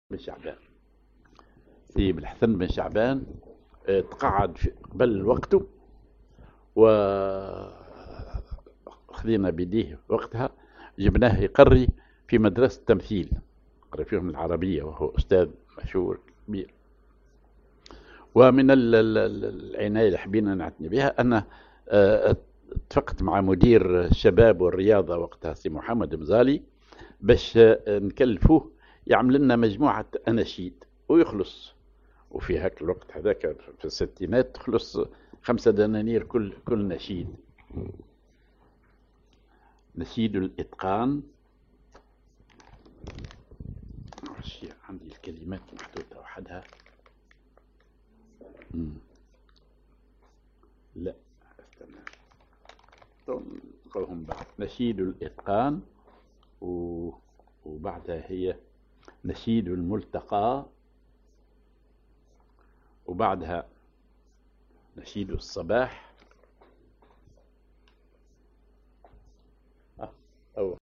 Maqam ar كردي
genre نشيد